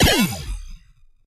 laser_big_shot.ogg